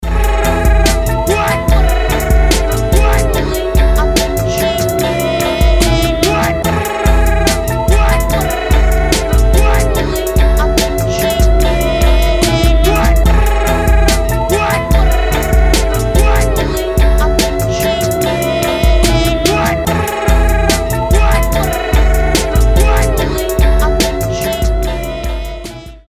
русский рэп
забавный голос